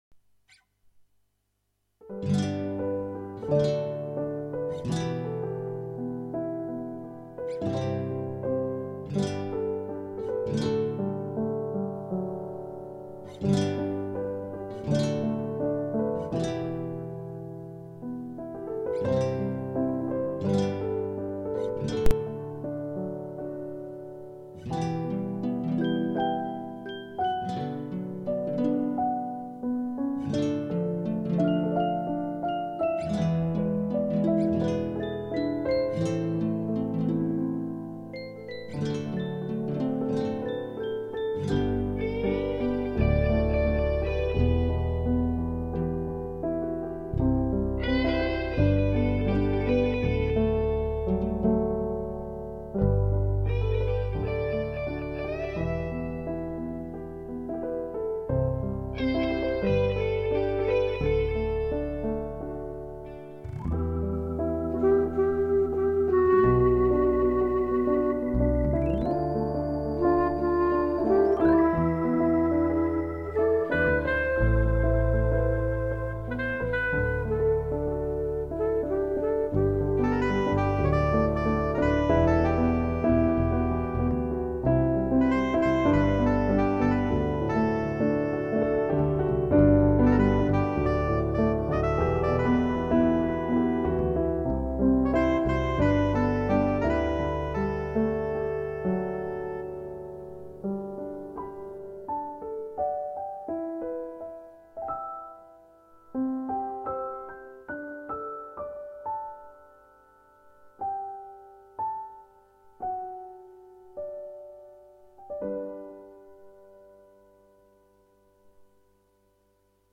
זה מרגיע כזה ,אהבתי ~-~ ....
זה הקלטה של כל כלי בנפרד, וכמה הקלטות אחת על השניה.